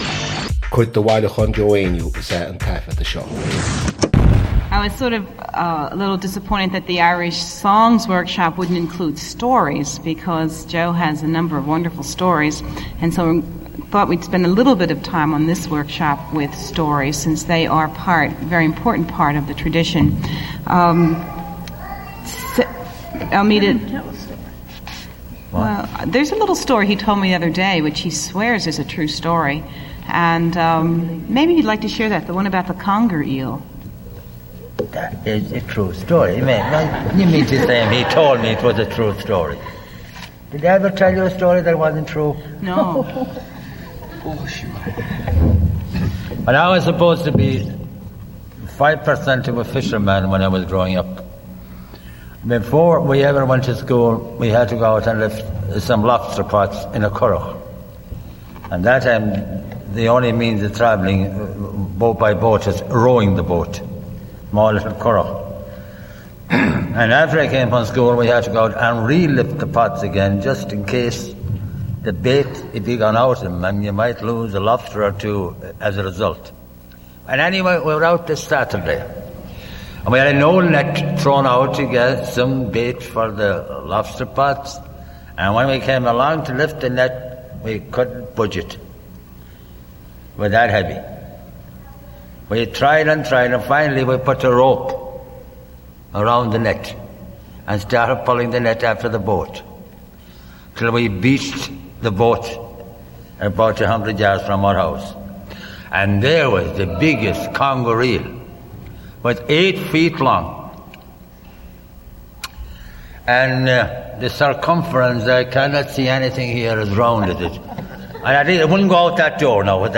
• Catagóir (Category): story.
• Ocáid an taifeadta (Recording Occasion): workshop.